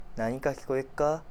Aizu Dialect Database
Type: Single wh-question
Final intonation: Rising
WhP Intonation: Peak
Location: Aizuwakamatsu/会津若松市
Sex: Male